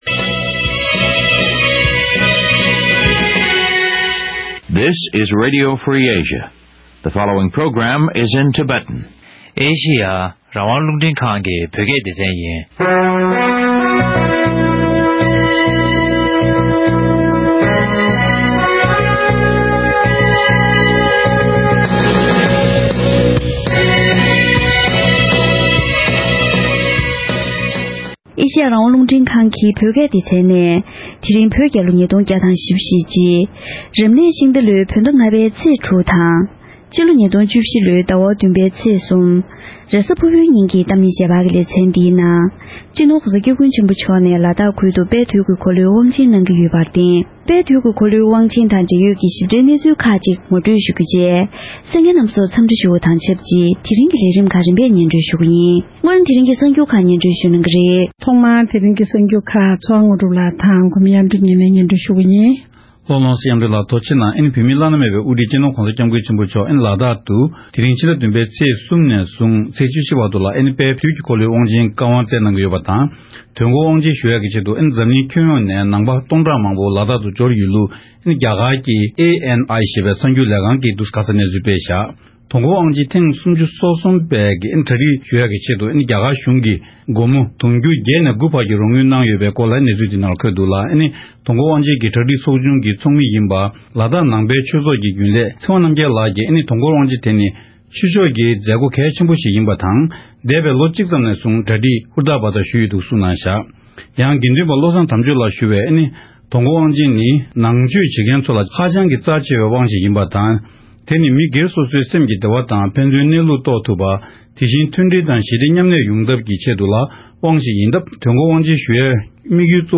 ༄༅། །དེ་རིང་གི་གཏམ་གླེང་ཞལ་པར་ལེ་ཚན་ནང་།